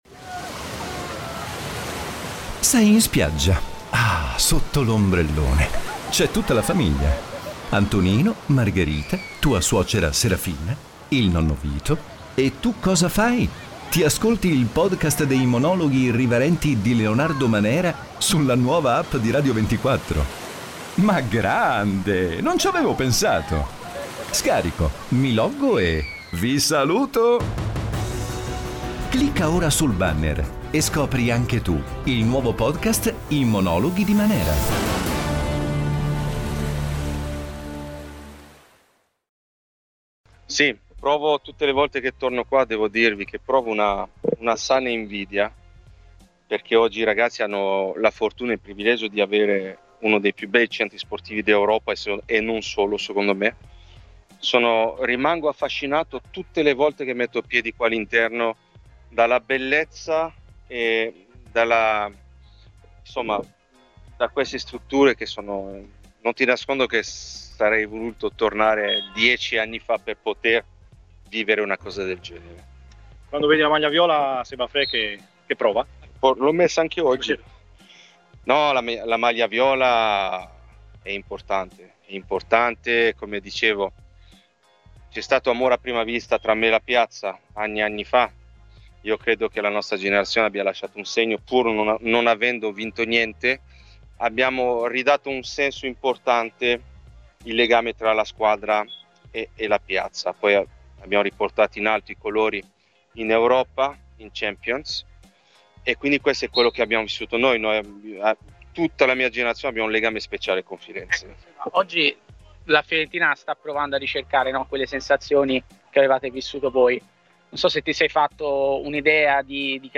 Sebastian Frey, presente al Viola Park per assistere alla seduta pomeridiana di allenamento della Fiorentina, ha risposto ad alcune domande dei nostri inviati.